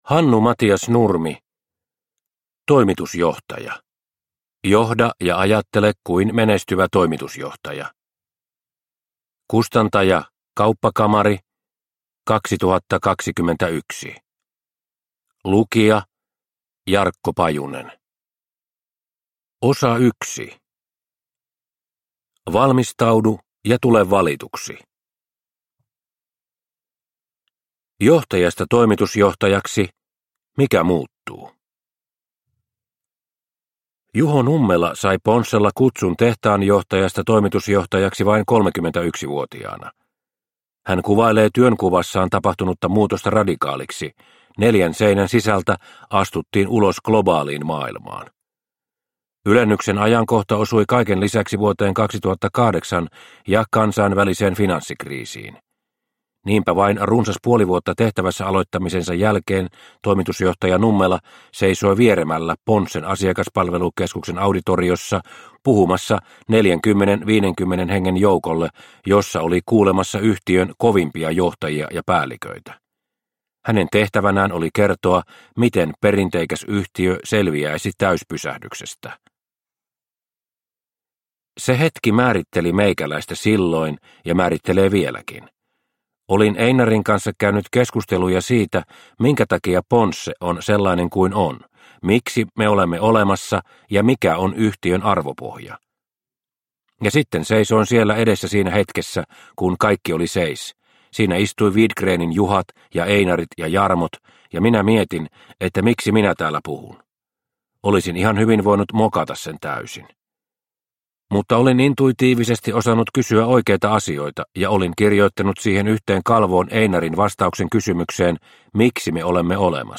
Toimitusjohtaja – Ljudbok – Laddas ner